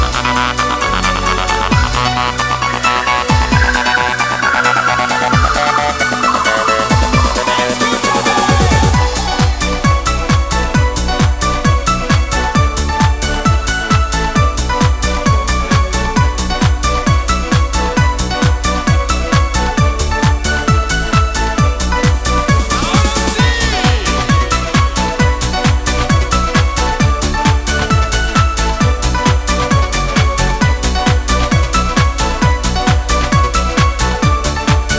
Жанр: Танцевальные / Хаус